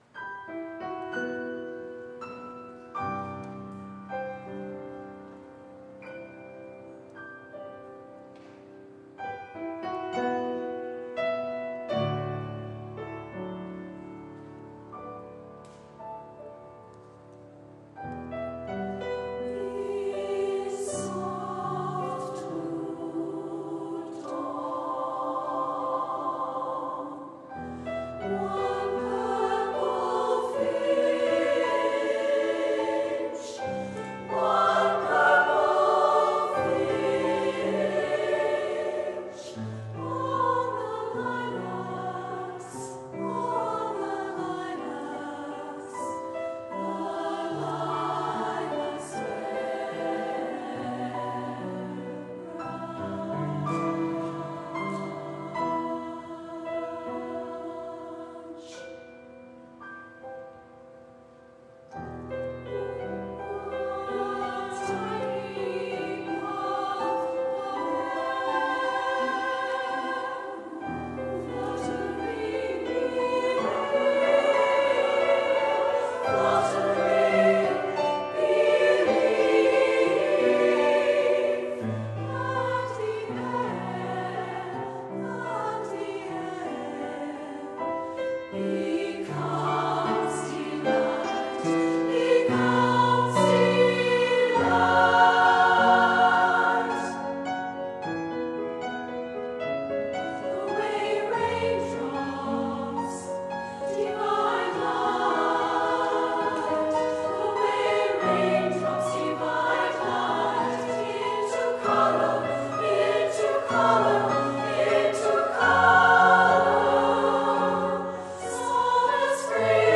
Voicing: SSA
Instrumentation: piano